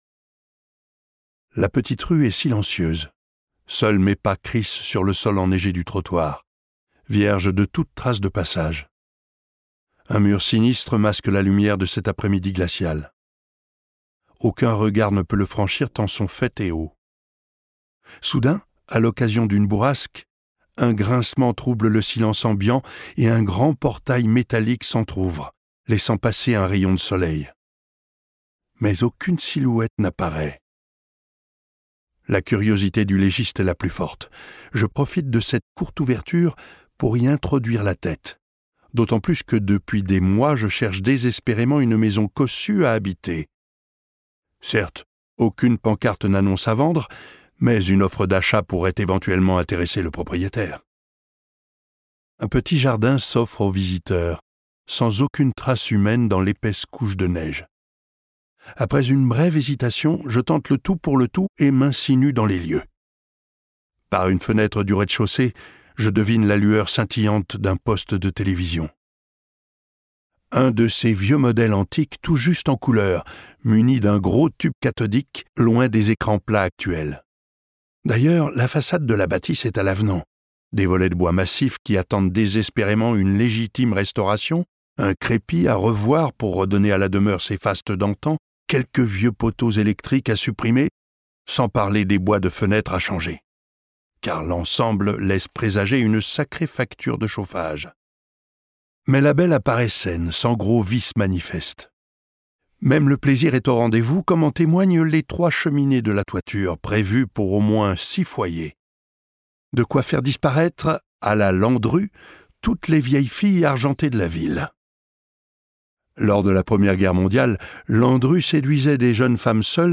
Expérience de lecture